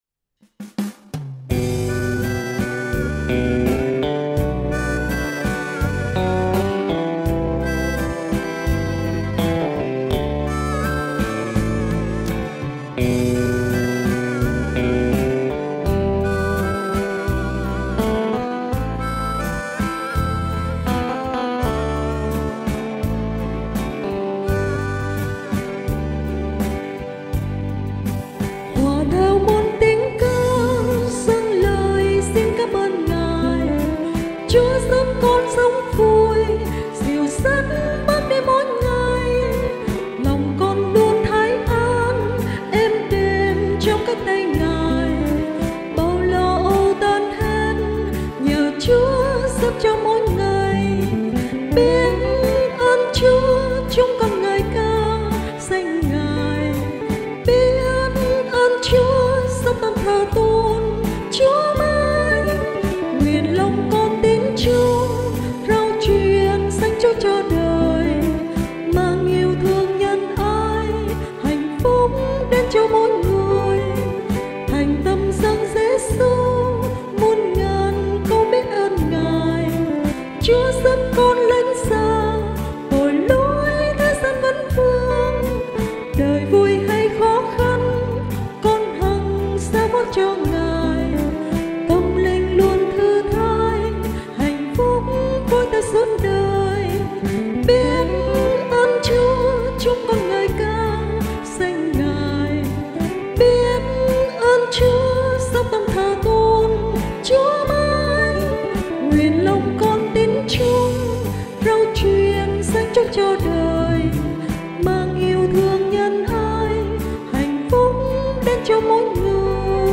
Bài hát cảm tạ: BIẾT ƠN CHÚA